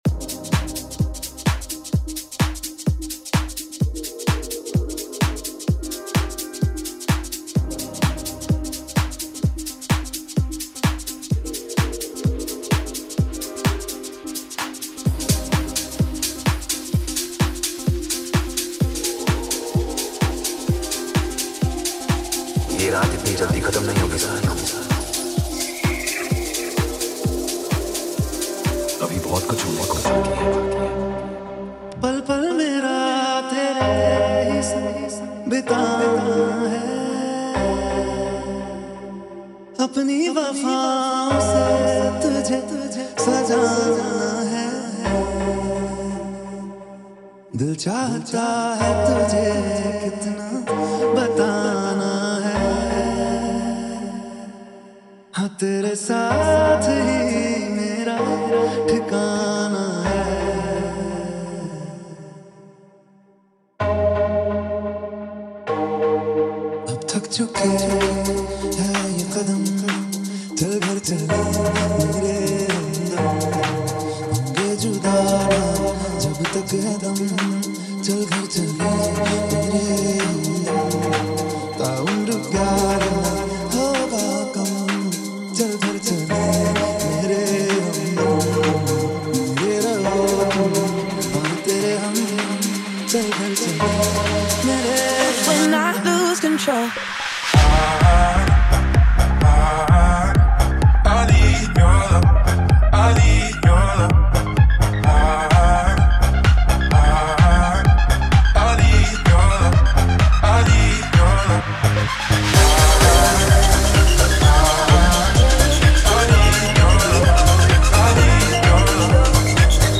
Category : Bollywood DJ Remix Songs